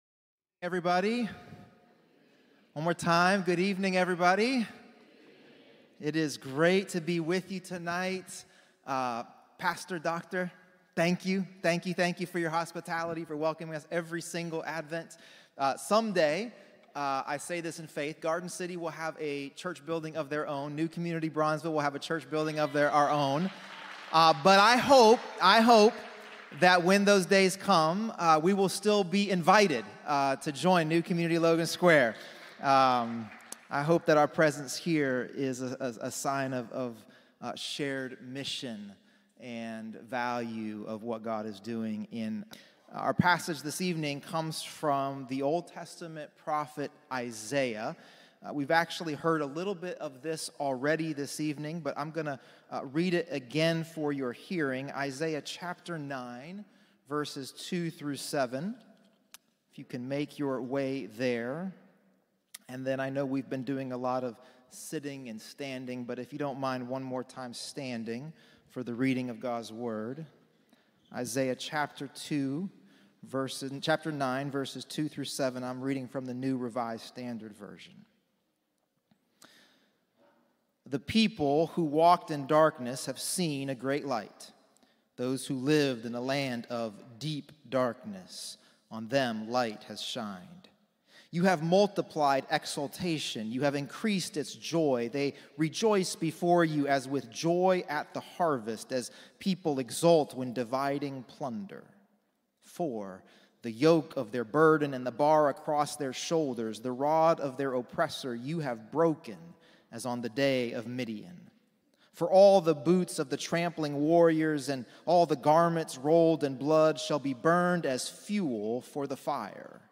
Sermons – New Community Covenant Church